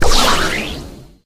buzz_ulti_atk_01.ogg